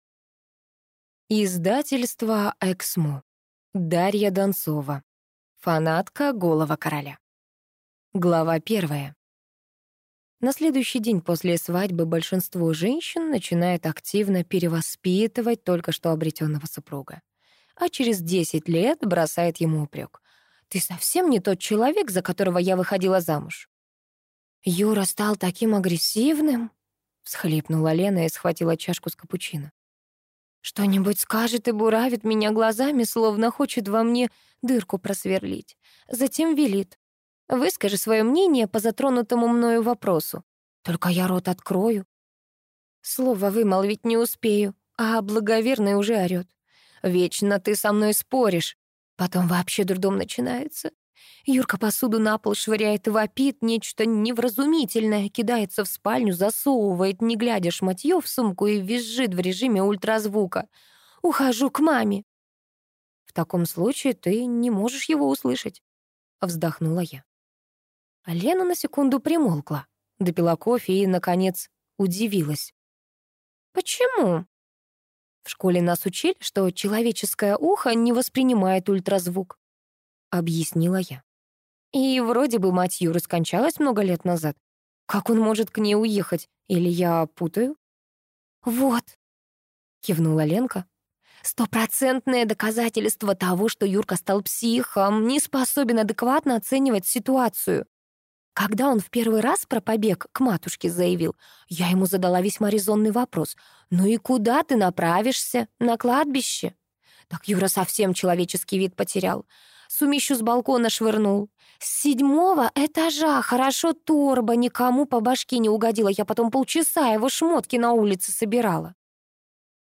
Аудиокнига Фанатка голого короля | Библиотека аудиокниг